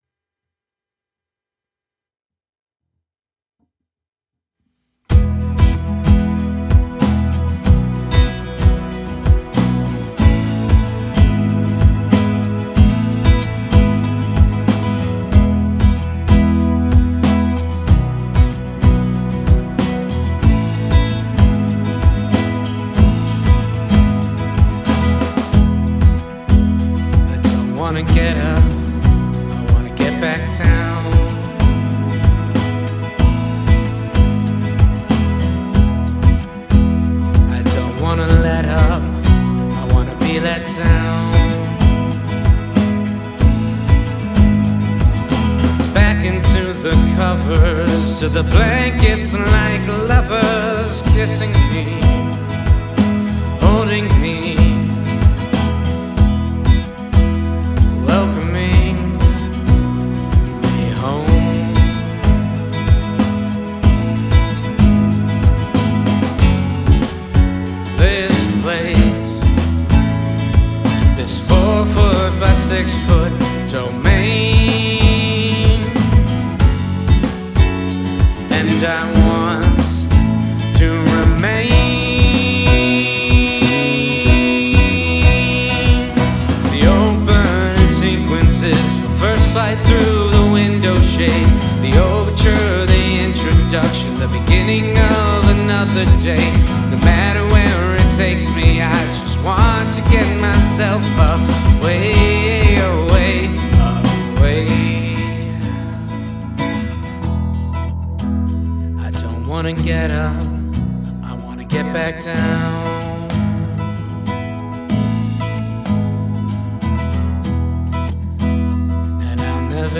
Original Song - "No Place To Go"